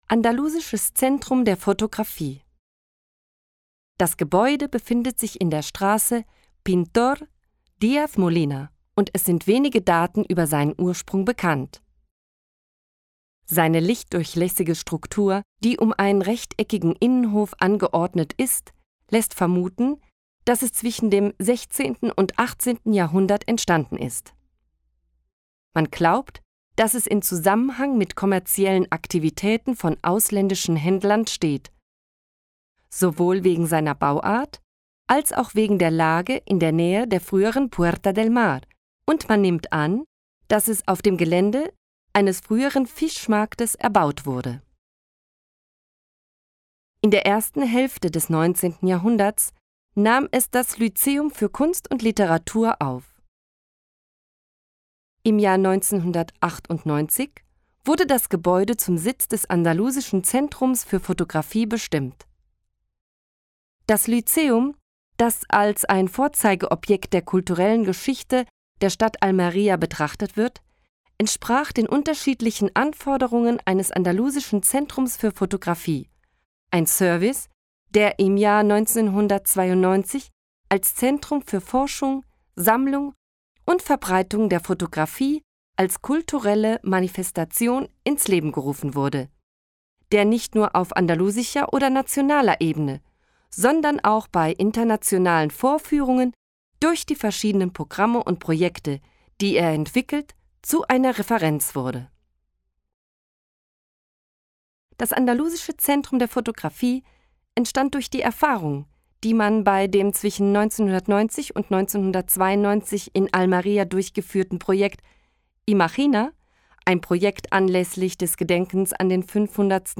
AUDIOGUIA-ALMERIA-ALEMAN-25-centro-andaluz-de-fotografia.mp3